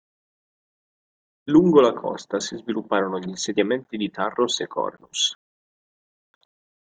Read more Noun Verb Frequency B1 Hyphenated as cò‧sta Pronounced as (IPA) /ˈkɔs.ta/ Etymology From Latin costa, from Proto-Indo-European *kost-.